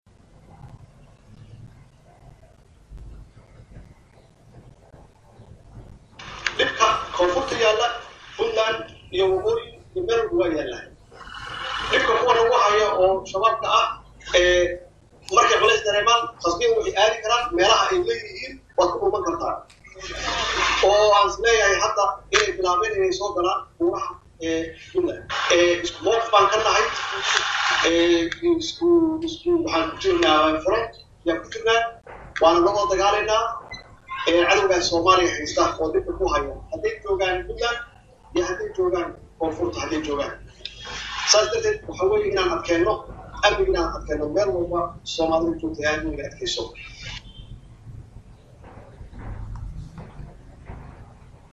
Madaxweynaha Jamhuriyadda Federaalka Soomaaliya Maxamed C/laahi Maxamed (Farmaajo) oo booqasho ku jooga degaanada Puntland ayaa sheegay sheegay xubno ka tirsan Shabaab ay ku soo carareen buuraha Galgala ee Puntland.
codka-farmaajo-shabaab-oo-baxsad-ah.mp3